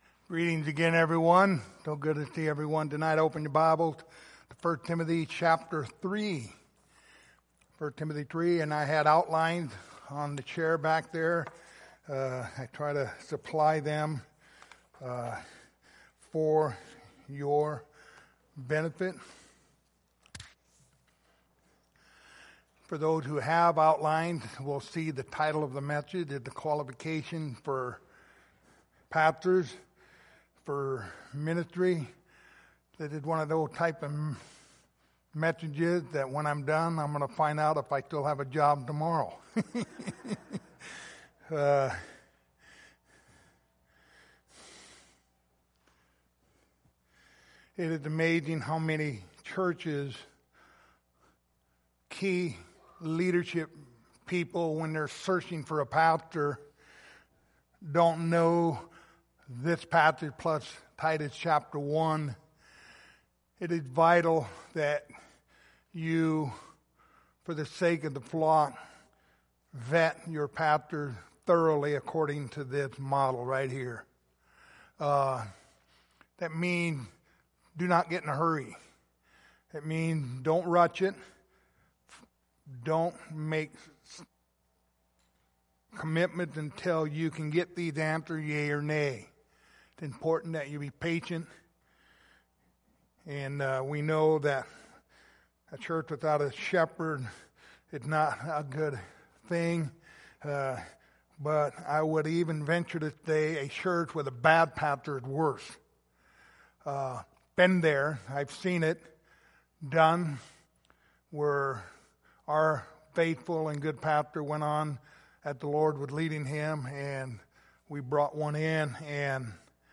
Passage: 1 Timothy 3:1-7 Service Type: Sunday Evening